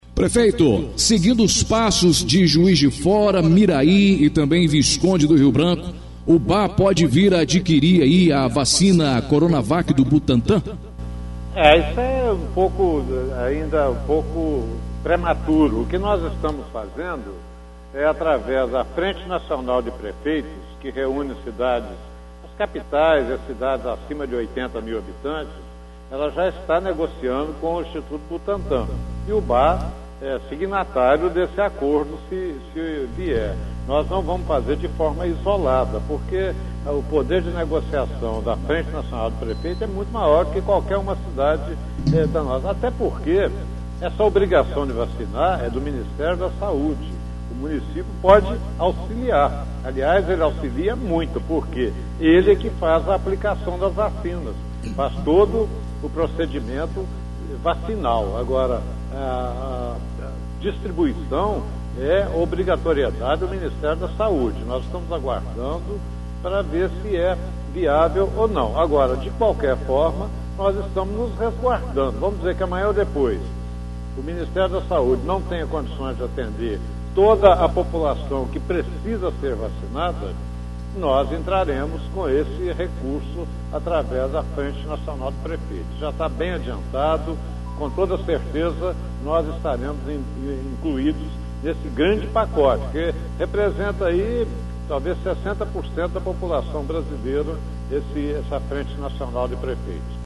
Prefeito de Ubá Edson Teixeira Filho em entrevista a Rádio Educadora AM/FM